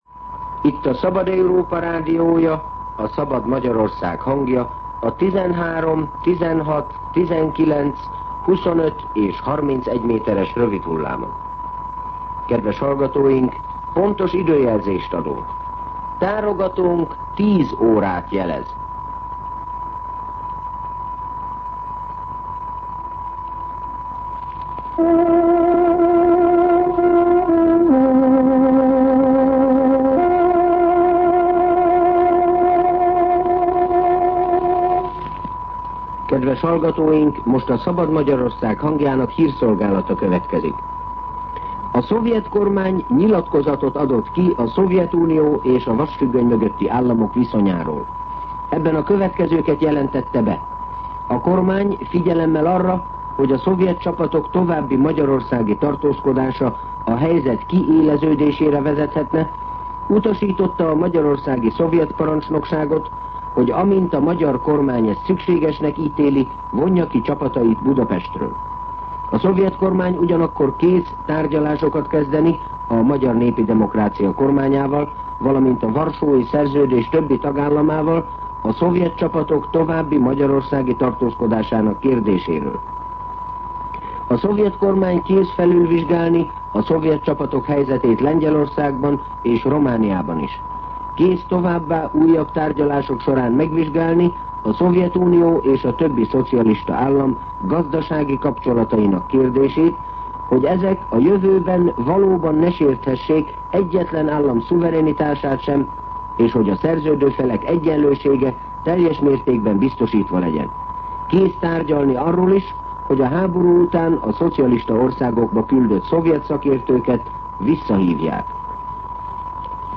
10:00 óra. Hírszolgálat